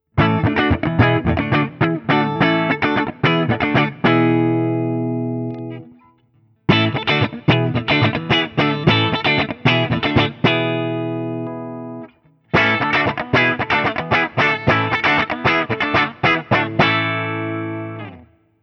The guitar has a great jangly sound from those LB1 pickups and accentuating that is where I’d find myself the most happy with this instrument.
ODS100 Clean
This is the first time I used my new Axe-FX III for recording which I did direct to Audacity to my Mac Pro.
For each recording I cycle through the neck pickup, both pickups, and finally the bridge pickup. All knobs on the guitar are on 10 at all times.
Guild-TBird-ST-ODS100-A.wav